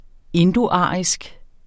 Udtale [ ˈendoˌɑˀisg ]